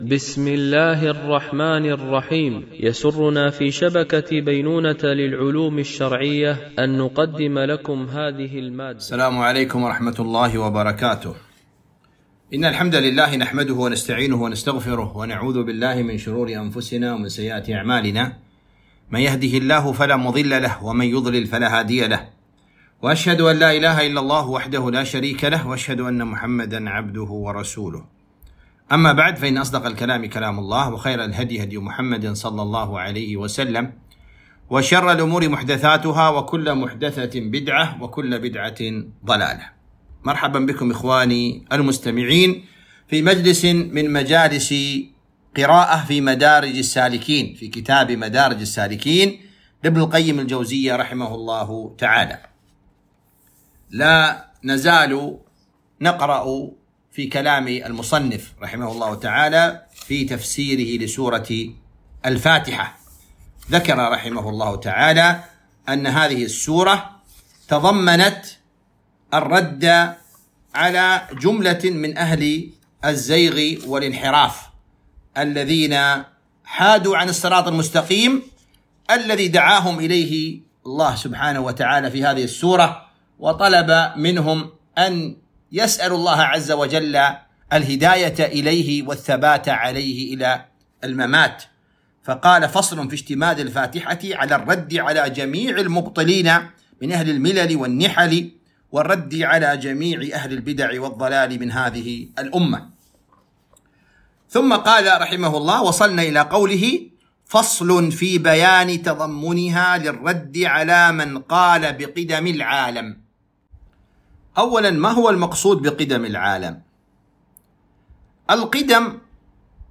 قراءة من كتاب مدارج السالكين - الدرس 09